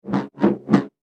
Звуки лазерного меча
Быстро: 3 раза nБысстро: 3 раза nБыстрро: 3 раза